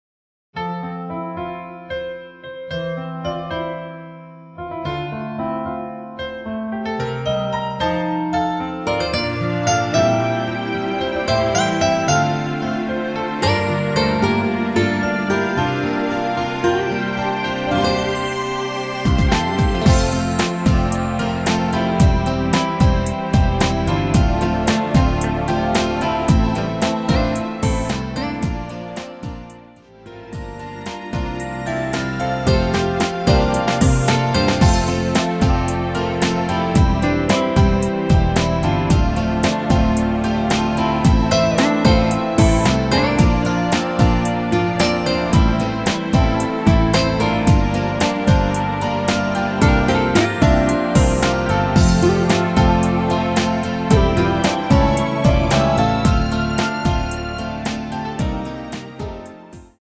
축가로도 잘 어울리는 곡 입니다.
앞부분30초, 뒷부분30초씩 편집해서 올려 드리고 있습니다.
중간에 음이 끈어지고 다시 나오는 이유는